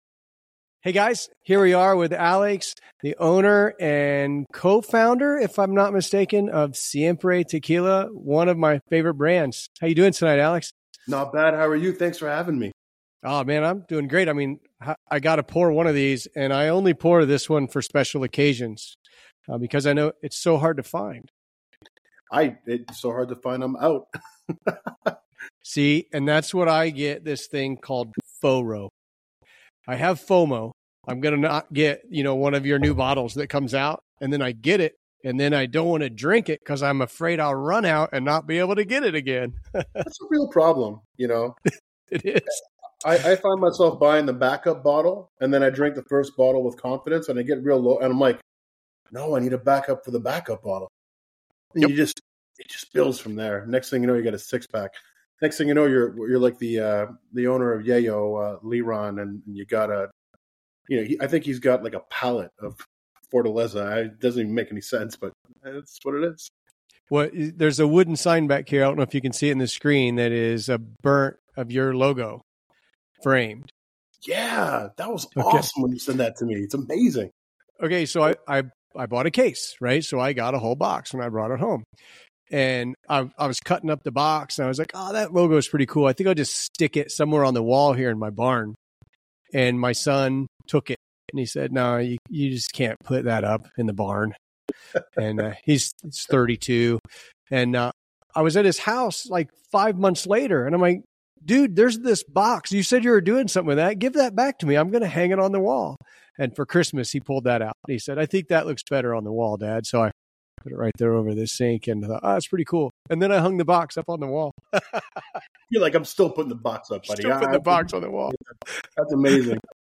Each episode, we bring you candid conversations with master distillers, brand founders, and agave experts who share their stories, craft secrets, and passion for tequila. Whether you’re a seasoned aficionado or just beginning your tequila journey, join us as we explore the rich culture, traditions, and innovations shaping this iconic spirit.